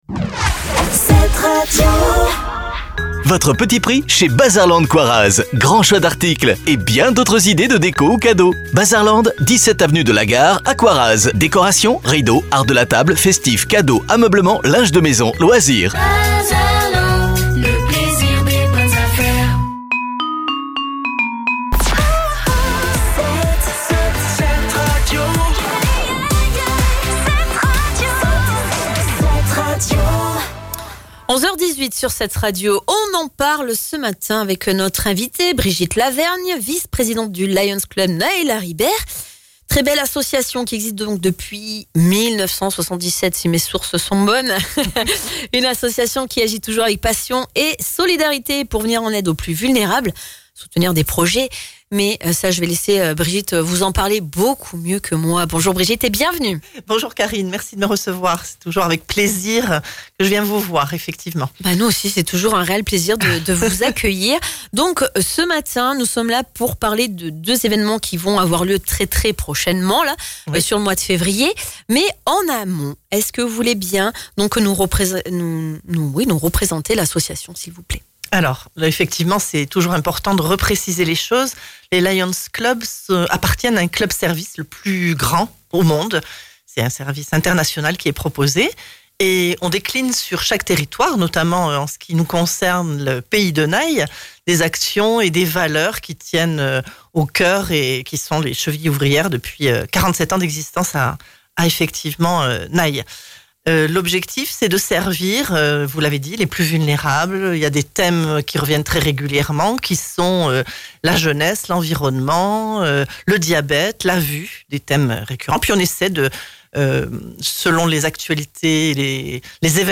dans La Grande Récré, la matinale de 7Radio.